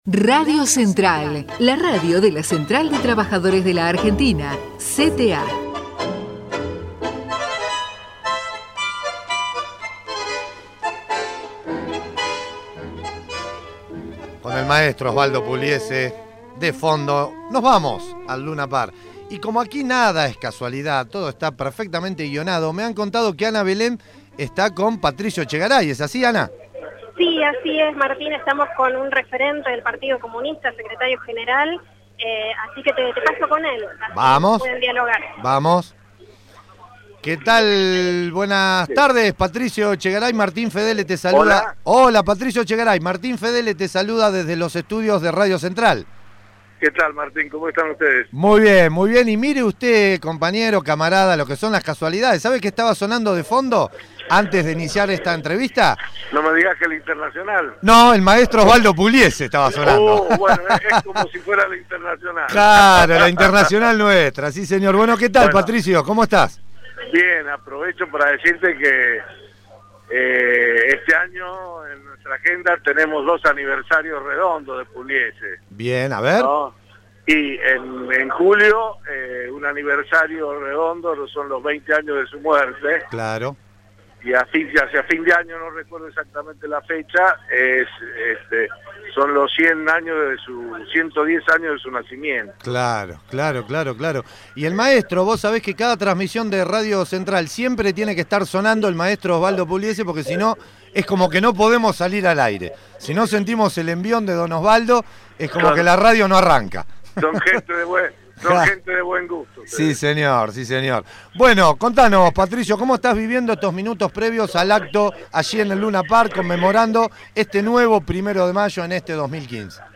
PATRICIO ECHEGARAY - ACTO 1º de MAYO - LUNA PARK – Central de Trabajadores y Trabajadoras de la Argentina